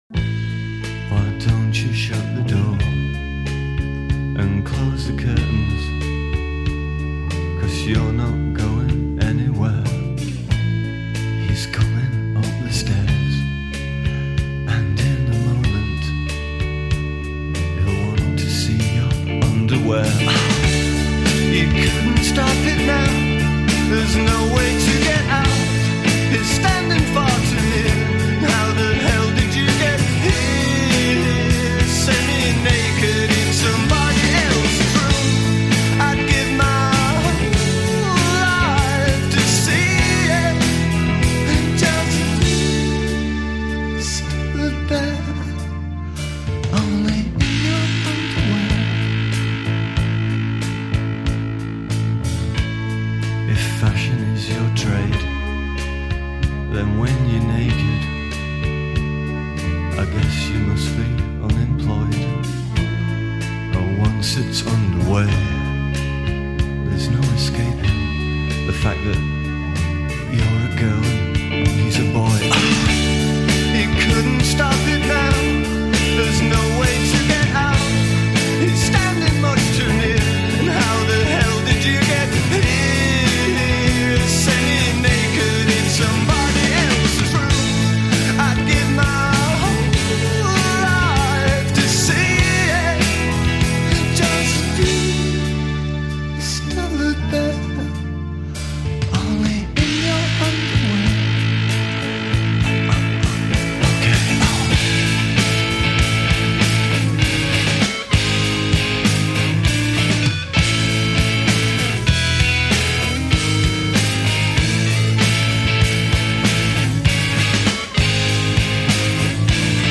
Britpop